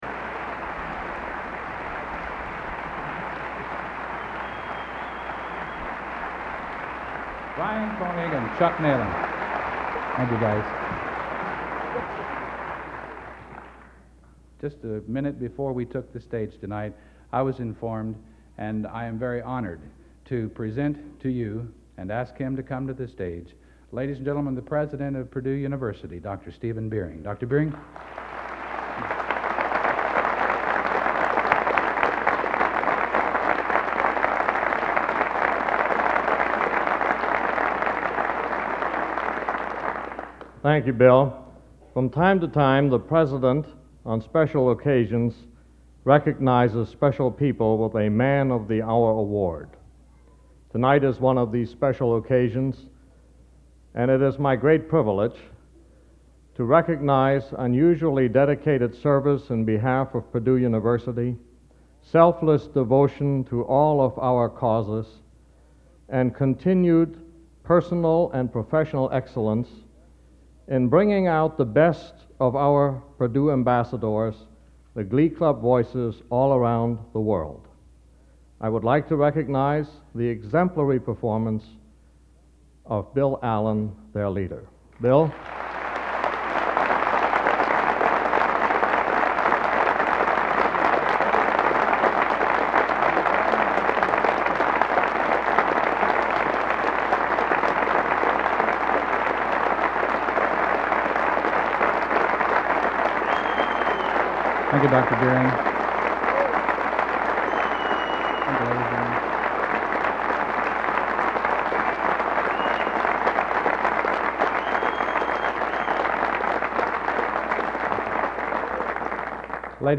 Collection: End of Season, 1986
Location: West Lafayette, Indiana
Genre: | Type: Director intros, emceeing |End of Season